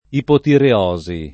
ipotireosi [ ipotire 0@ i ] s. f. (med.)